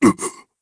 Dakaris-Vox_Damage_jp_01.wav